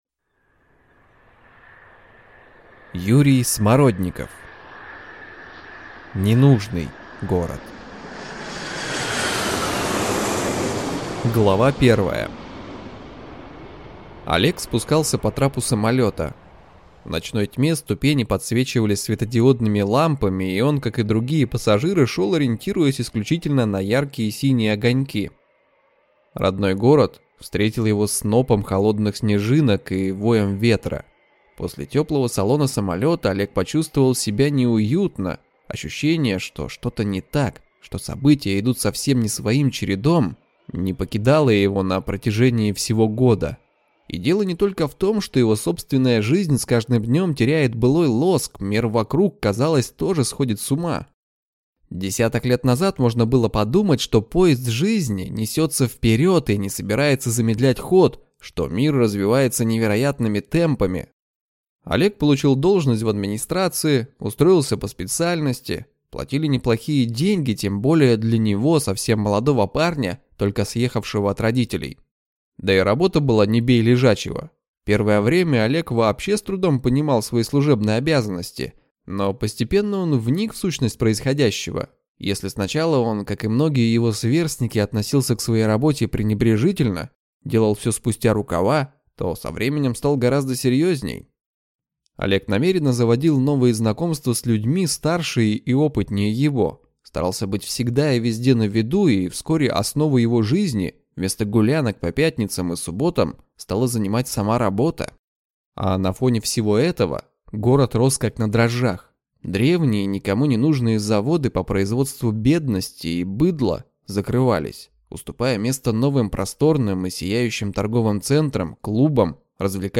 Аудиокнига Ненужный город | Библиотека аудиокниг